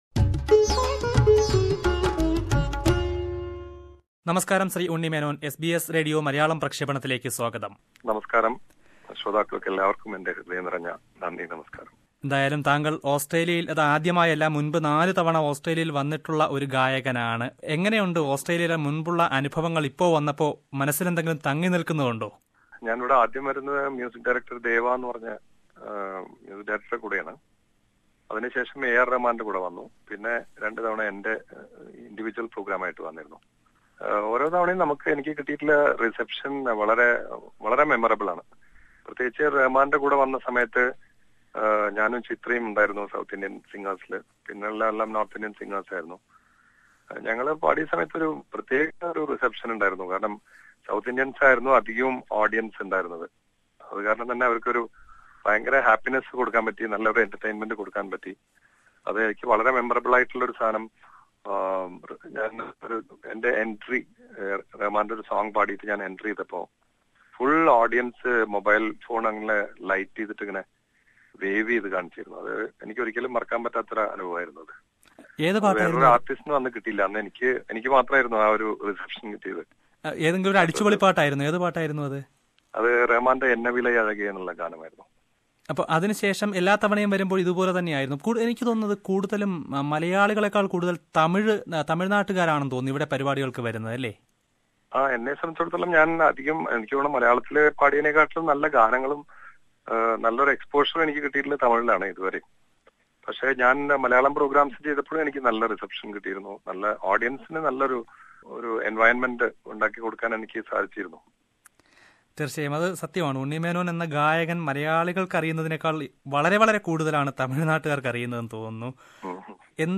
Starting from Brisbane, the artists will tour to Adelaide, Melbourne and Sydney. Prior to the stages, Unni Menon talks to SBS Malayalam about his rise as a famous playback singer in South India.